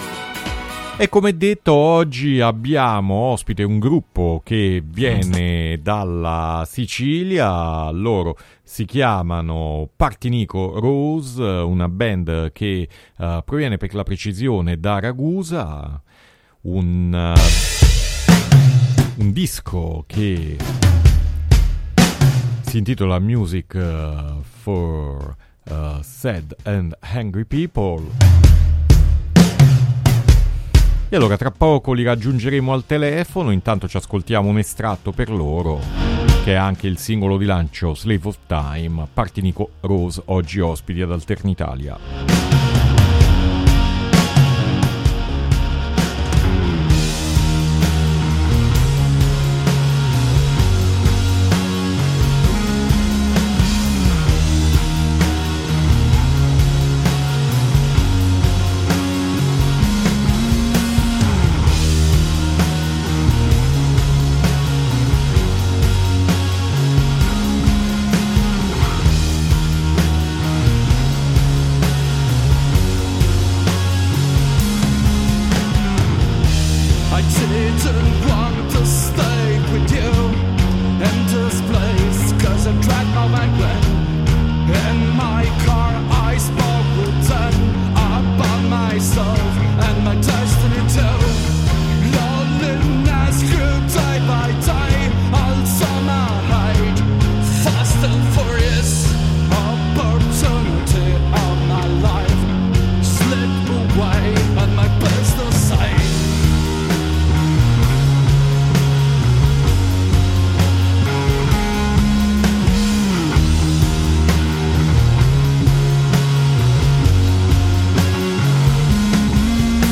Intervista Partinico Rose | Radio Città Aperta